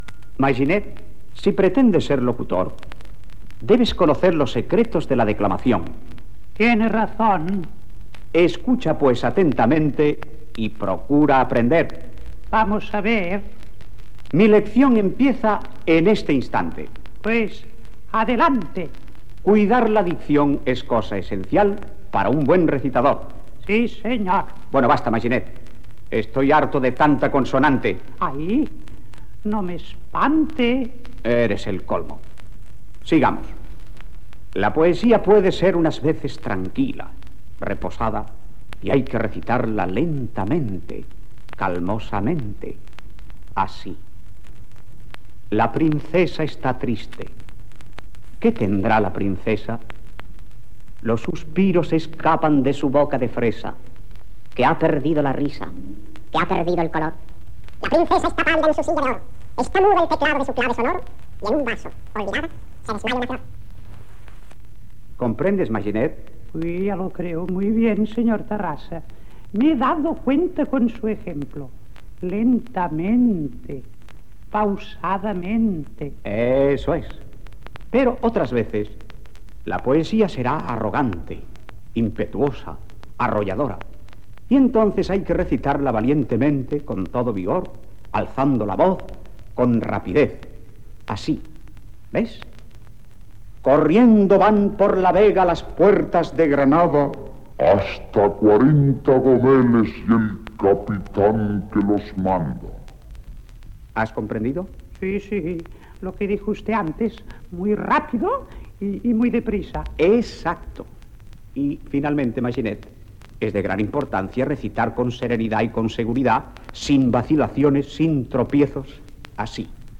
Maginet fa una malifeta quan s'esregistra el disc i després canta una cançó amb canvis de velocitat
Infantil-juvenil